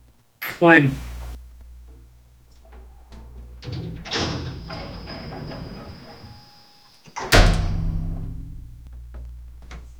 haptic vibration sound relaxing D note 95 bpm soft melody sliding from left to right flying delay softly modulated with slight soft echo 0:20 Immersive elevator door closing: metallic clank as doors engage, followed by smooth sliding inward with subtle motor whirring, faint rattles, and slight echo inside a shaft. Ends with a firm click as doors fully shut.
immersive-elevator-door-c-ot7lav5f.wav